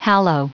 Prononciation du mot hallow en anglais (fichier audio)
Prononciation du mot : hallow